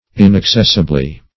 In`ac*cess"i*ble*ness, n. -- In`ac*cess"i*bly, adv.